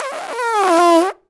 pgs/Assets/Audio/Comedy_Cartoon/fart_squirt_16.WAV at master
fart_squirt_16.WAV